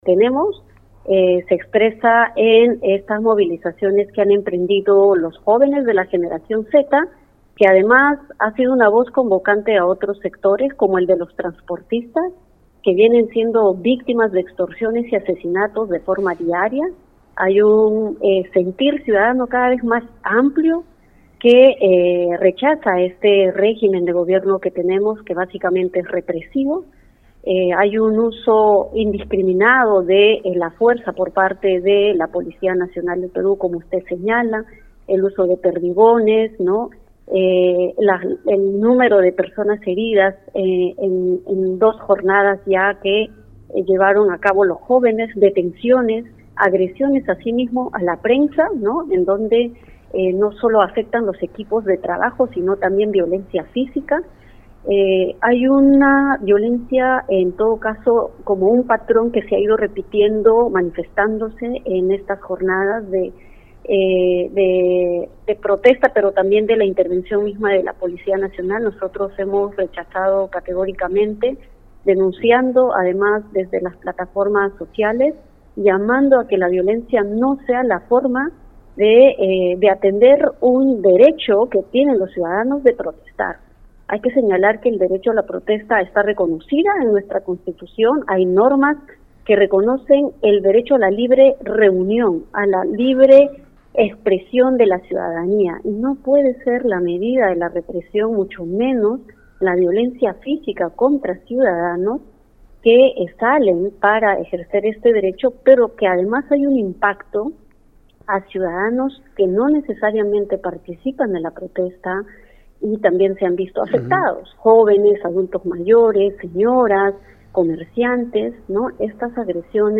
Entrevista completa en nuestro canal YouTube